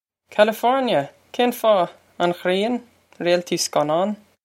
Pronunciation for how to say
California! Kayn faw? On ghree-on? Rayl-tee skuh-nawn?
This is an approximate phonetic pronunciation of the phrase.